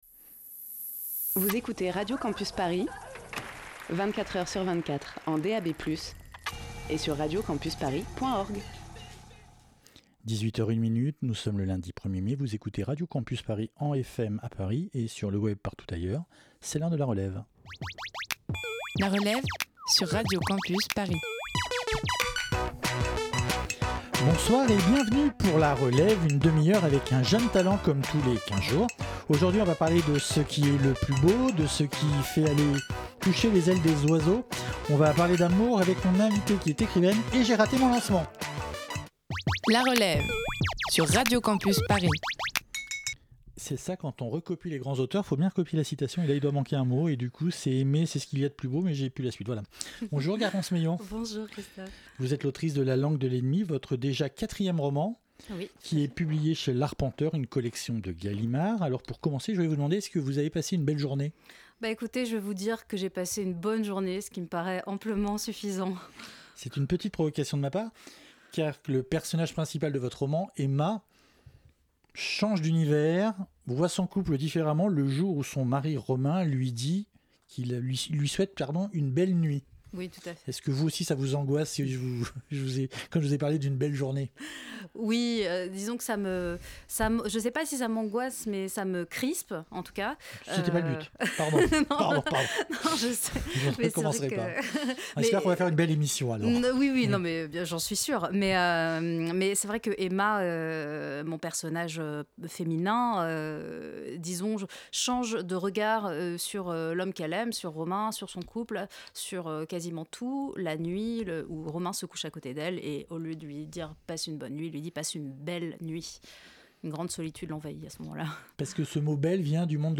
Type Entretien